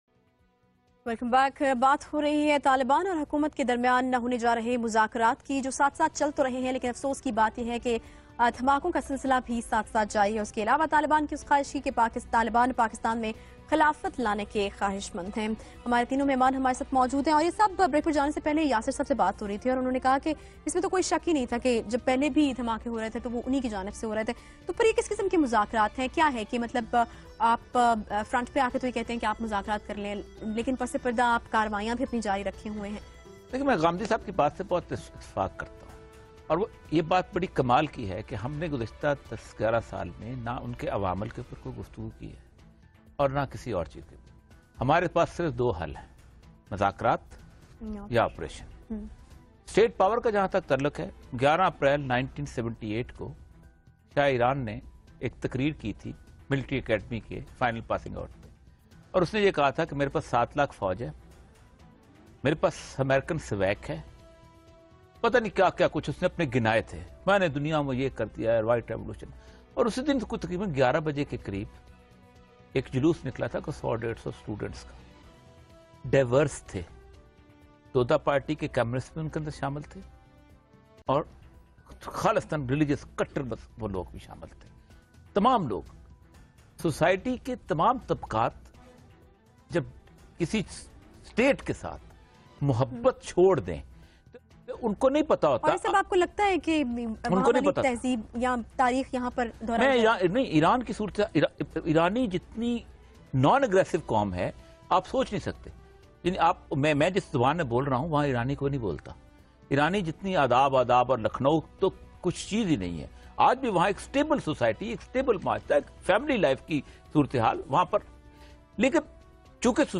in a Tv program on Geo Tez